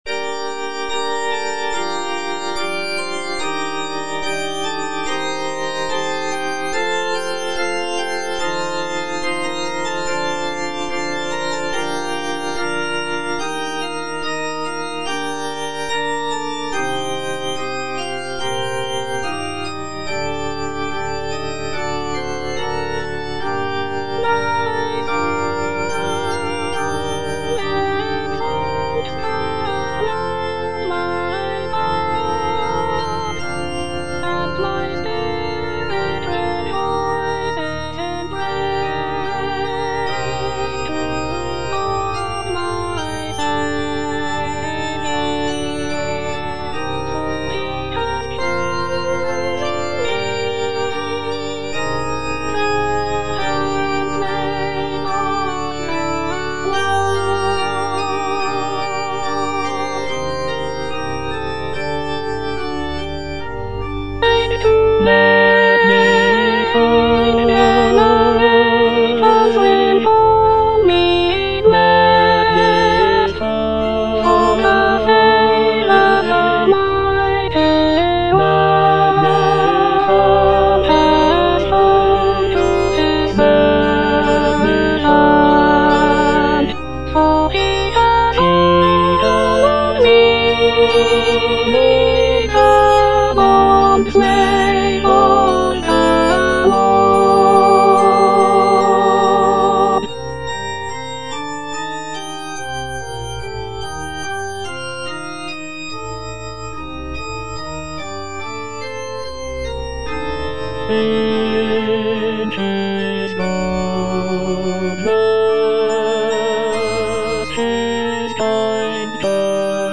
Soprano (Emphasised voice and other voices)
choral piece